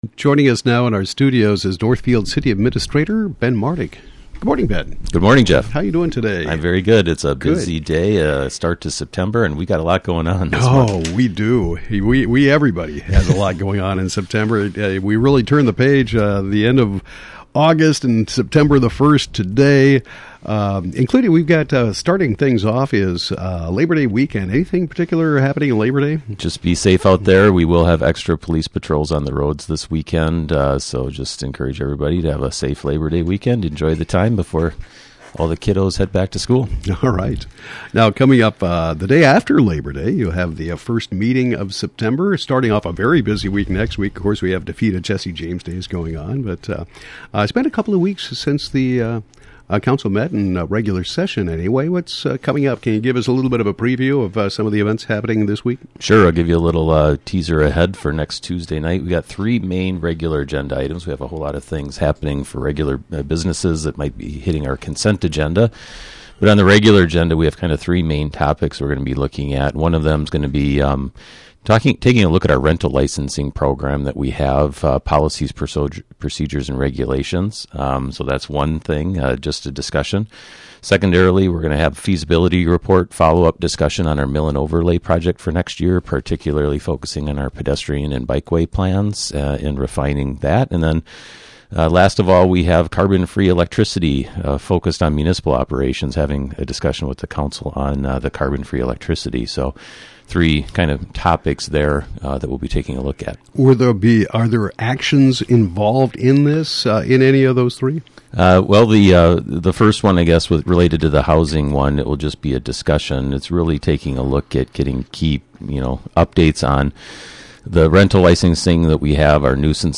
Northfield City Administrator Ben Martig provides a preview of agenda items to be addressed by the City Council on Tuesday, September 7, and more.